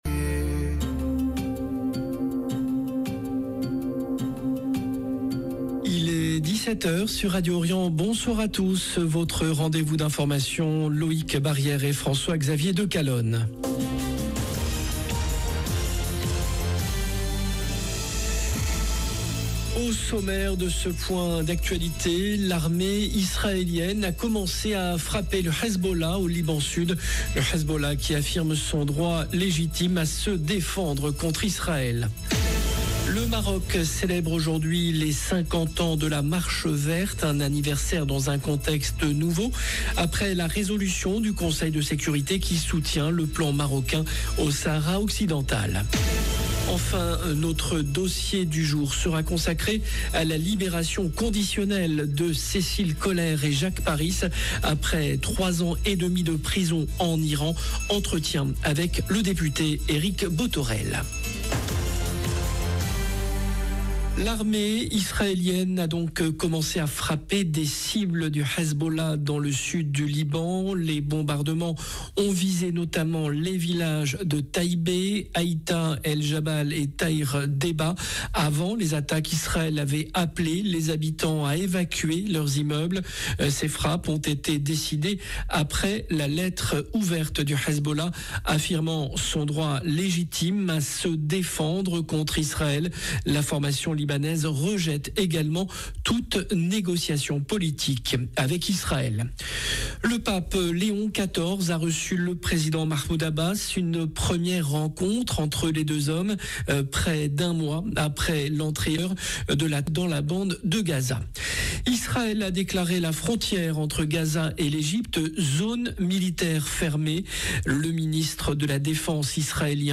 JOURNAL DE 17H
Entretien avec le député Eric Bothorel 0:00 1 sec